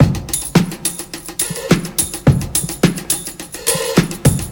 • 106 Bpm Drum Loop F# Key.wav
Free drum loop sample - kick tuned to the F# note. Loudest frequency: 3833Hz
106-bpm-drum-loop-f-sharp-key-yg9.wav